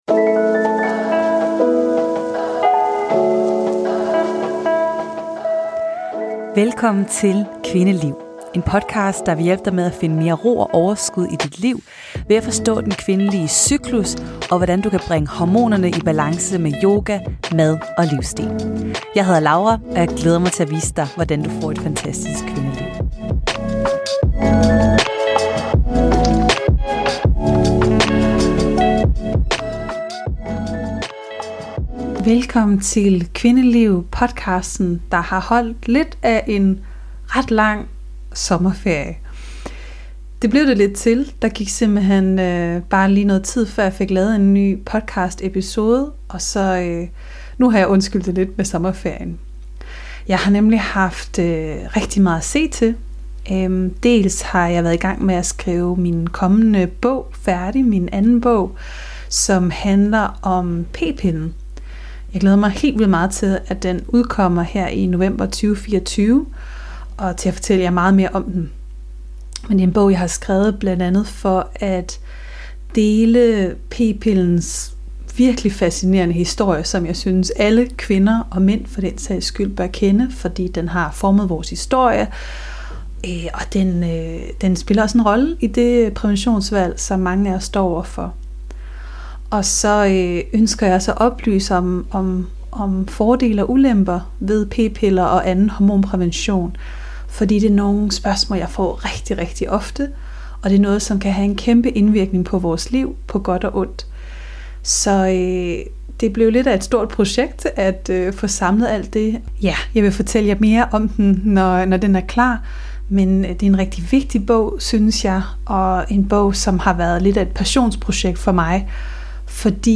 En groundende meditation hvor du møder din maskuline / feminine energi